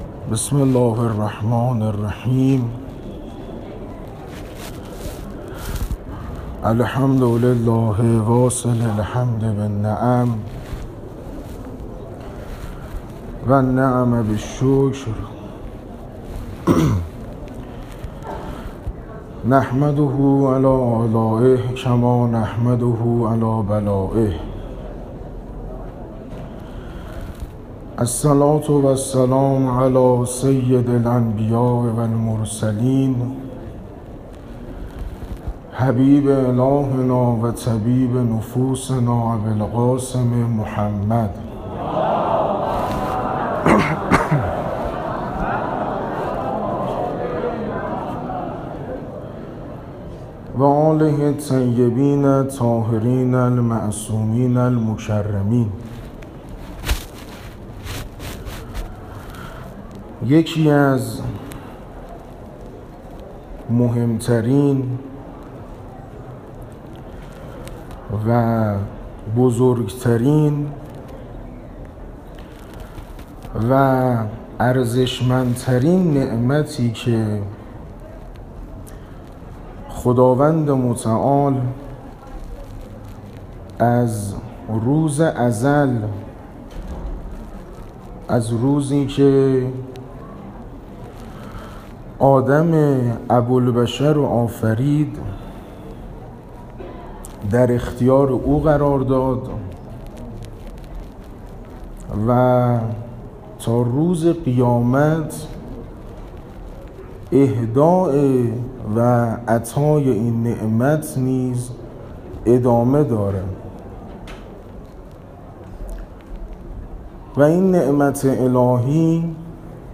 سخنرانی دهه اول محرم مسجد محمد رسول الله صفات شیعه ۱